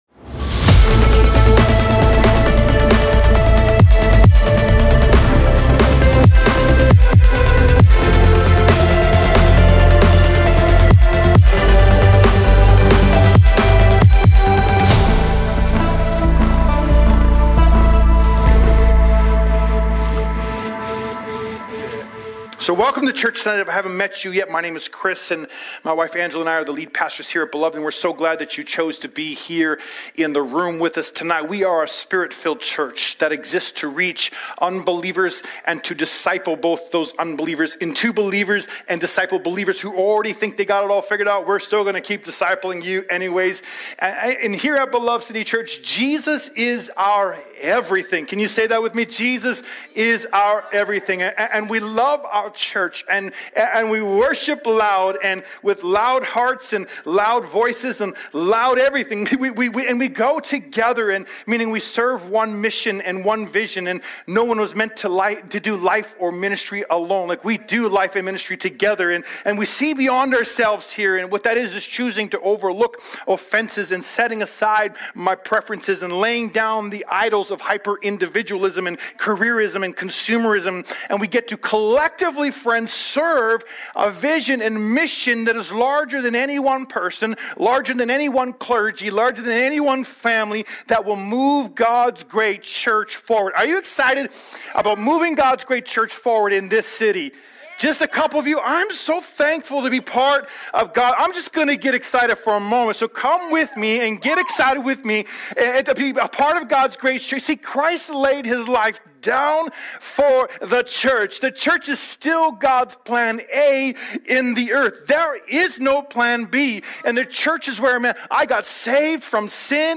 Sermons | Beloved City Church